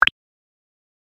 menu-options-click.ogg